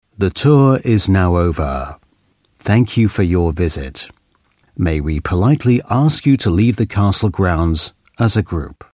Native Speaker
Englisch (UK)
Audioguides